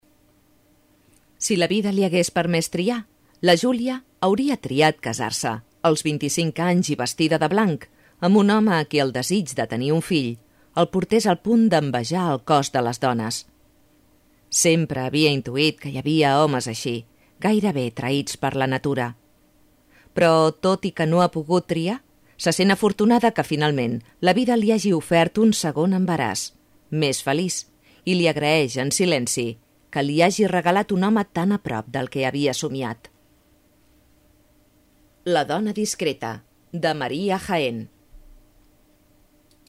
Voz media y joven.
kastilisch
Sprechprobe: Industrie (Muttersprache):